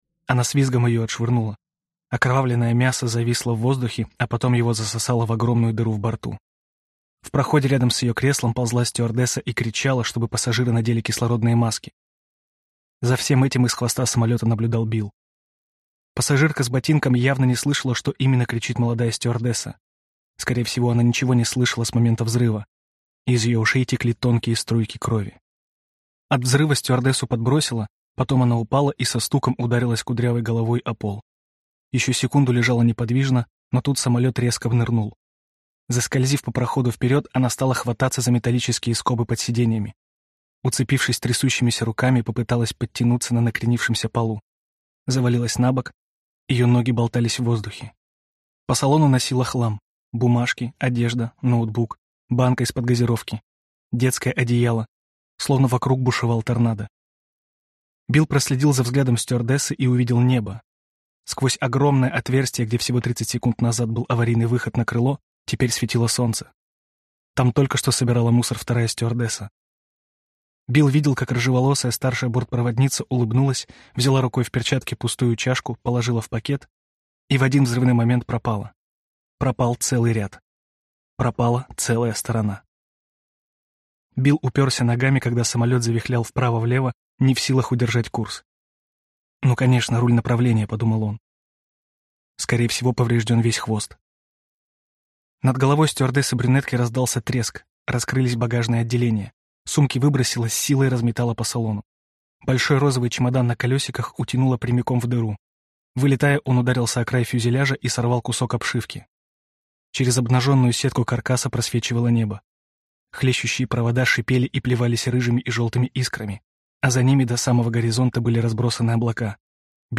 Аудиокнига Падение | Библиотека аудиокниг